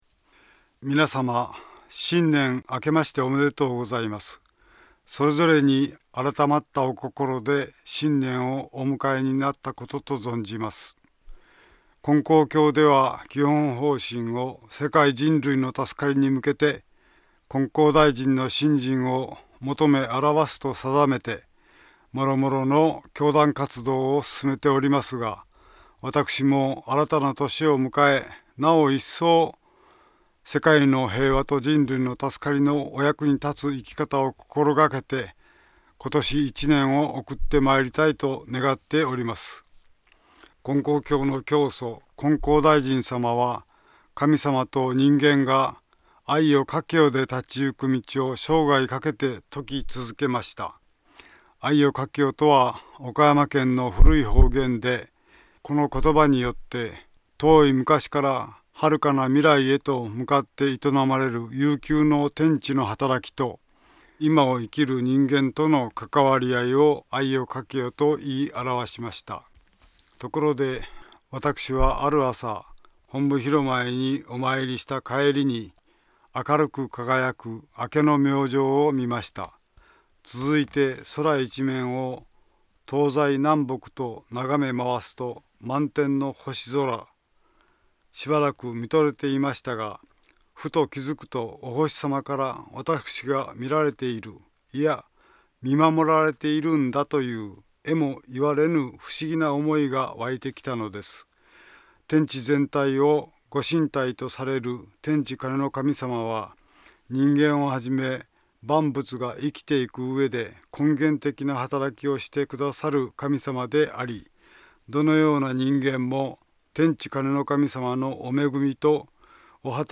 ●年頭放送